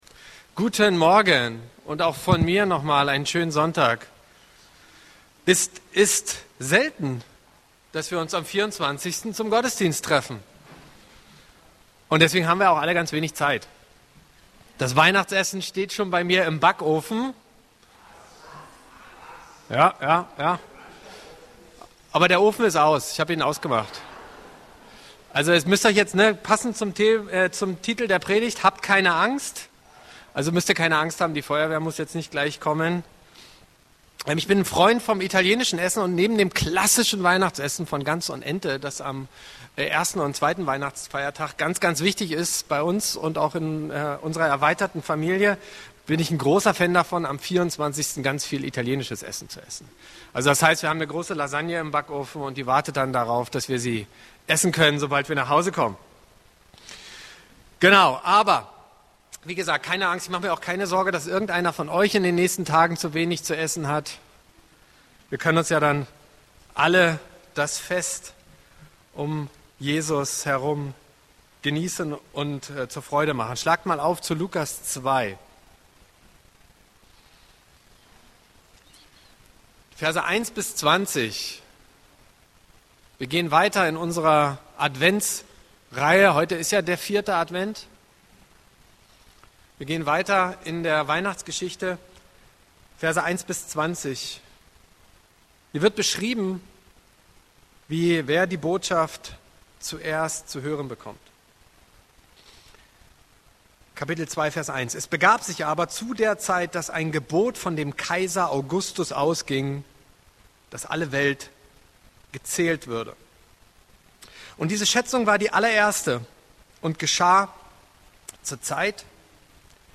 E-Mail Details Predigtserie: Advent Datum